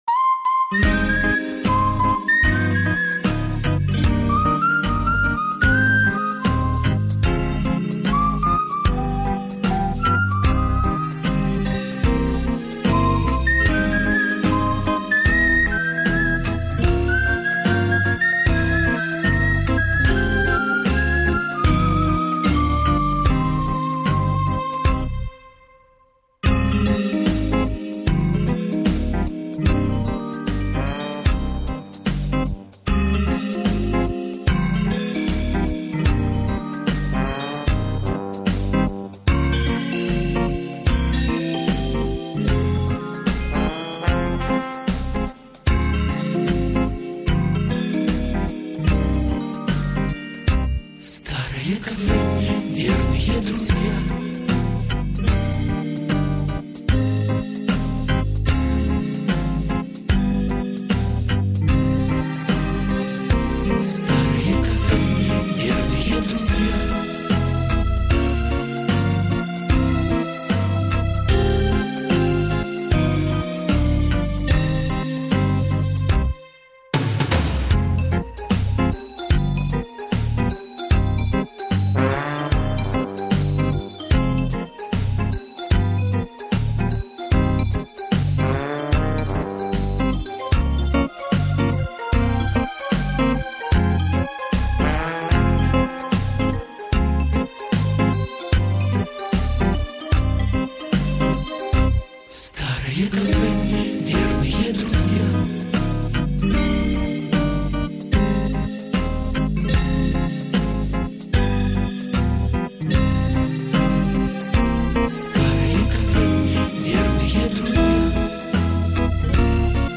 минусовка версия 31281